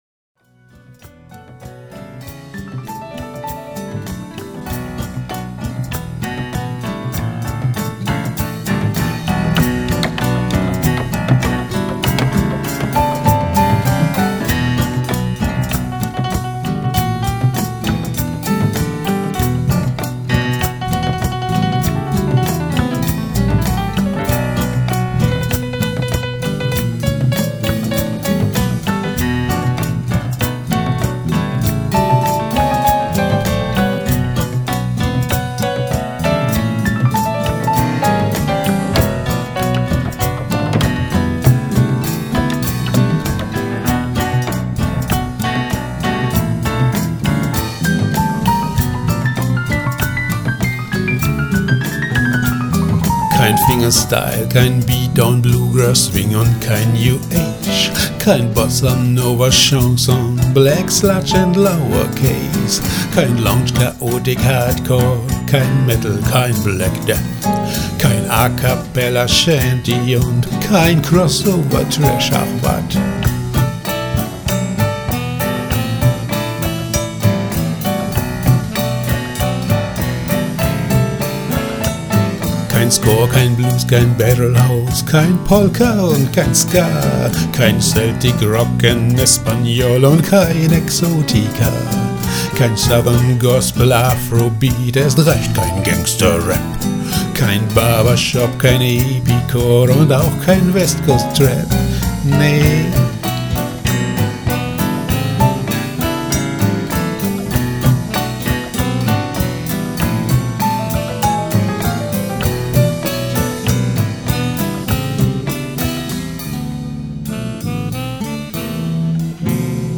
CD * Singer-Songwriter-Chanson-Blues-Rock-Jazz-Experimental
Piano, E-Piano, Organ, Conga, Guiro